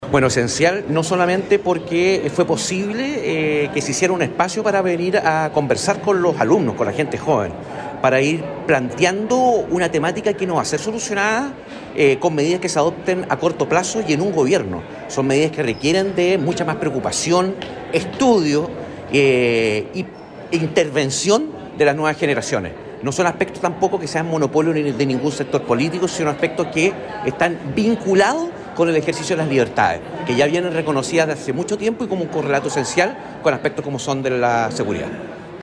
En medio de la apretada agenda de su visita al Biobío, la ministra Carolina Tohá llegó hasta la Universidad de Concepción para participar del seminario “Futuro y seguridad pública en Chile”, organizado por la Facultad de Ciencias Jurídicas y Sociales, oportunidad en la que pudo exponer sobre la situación del país en seguridad y los desafíos y avances del Gobierno en dicha materia ante un público compuesto principalmente por académicos, funcionarios y estudiantes.